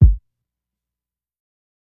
KICK_GROWTH.wav